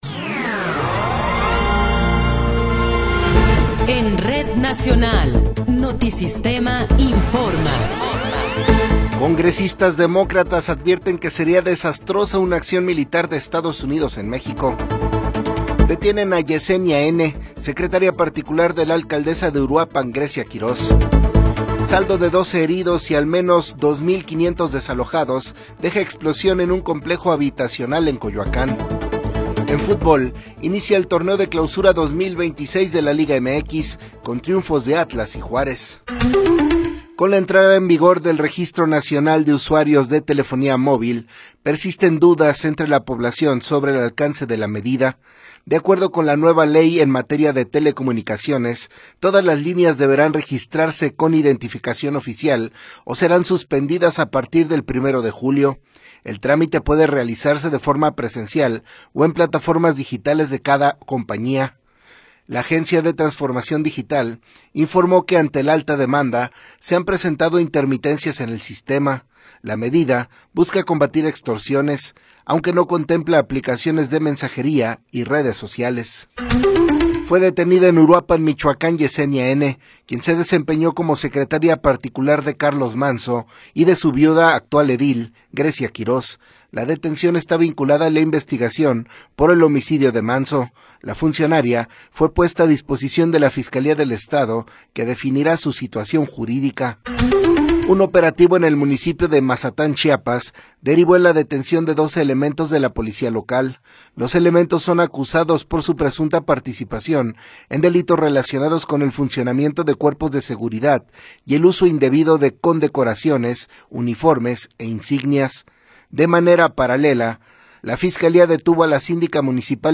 Noticiero 8 hrs. – 10 de Enero de 2026